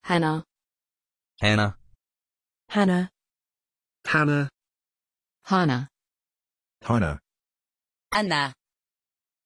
Pronunciation of Hana
pronunciation-hana-en.mp3